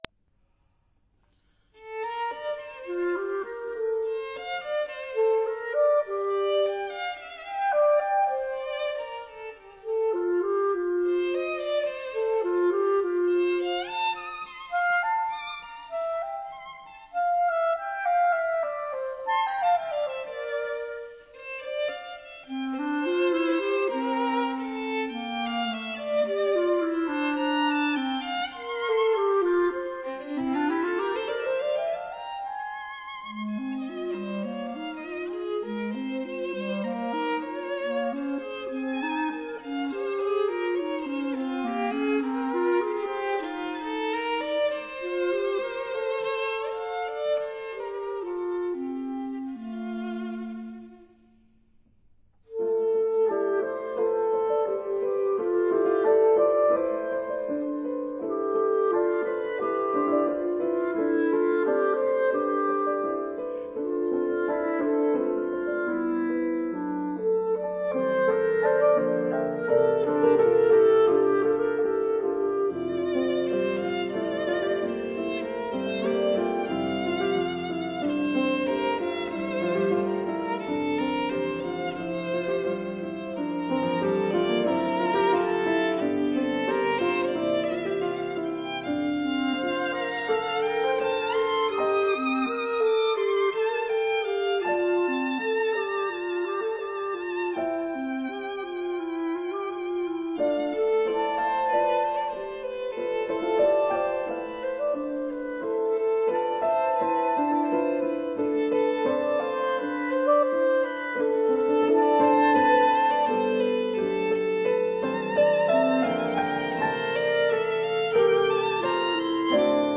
•(01) Suite, Op. 157b for violin, clarinet and piano
piano